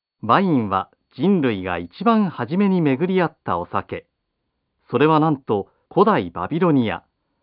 s3男性日本語　ワインは人類が最初に出会ったお酒 ...